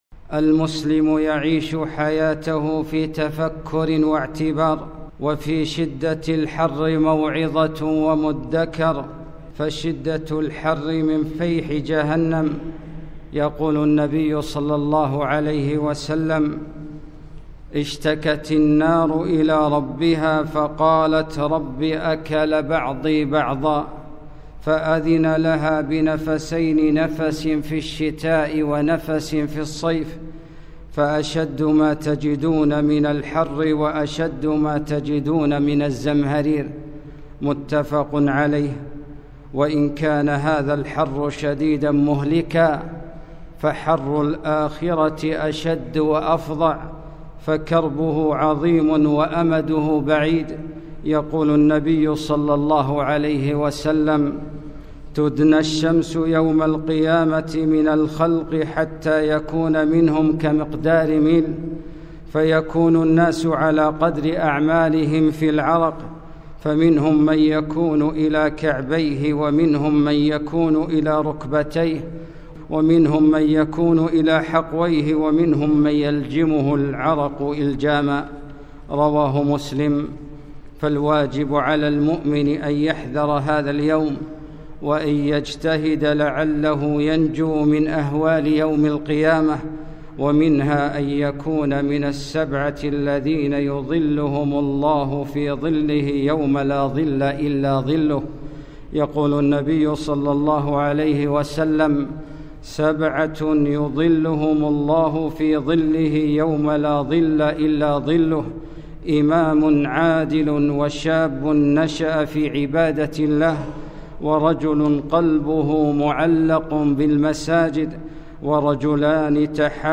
خطبة - قال حَسِّ